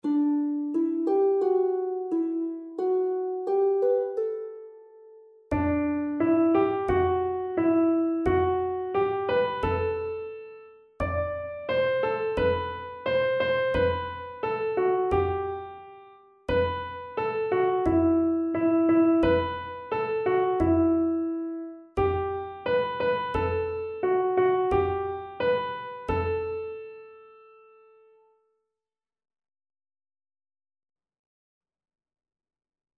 Koor zingt: 'Zie hoe hij gaat'